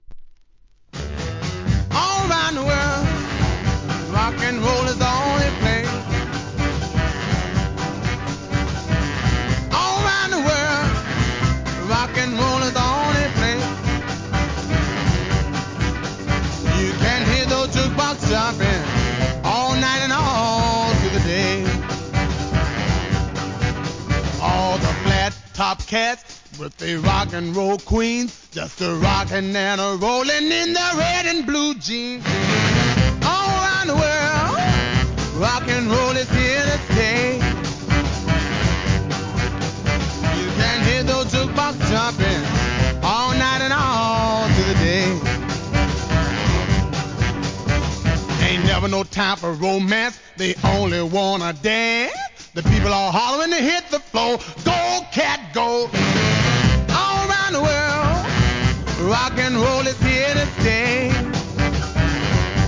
SOUL/FUNK/etc...
1956年、BLACK R&R最高潮!!!